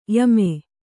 ♪ yame